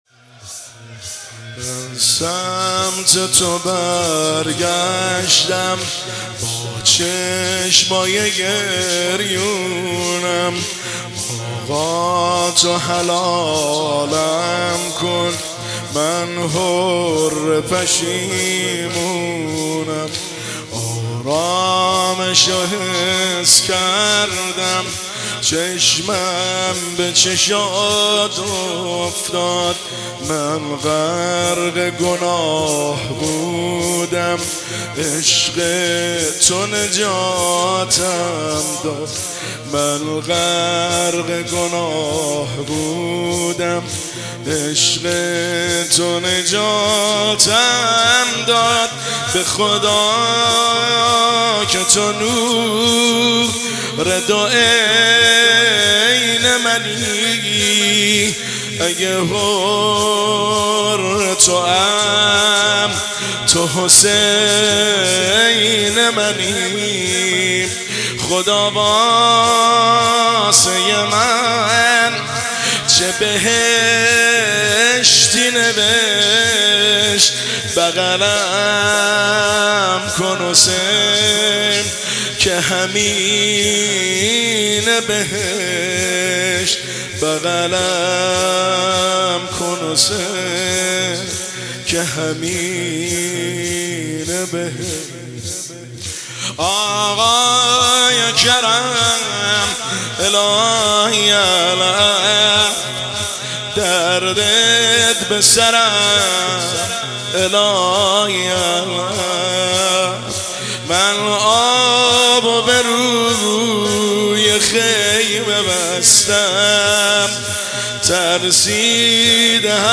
زمینه شب چهارم محرم الحرام 1404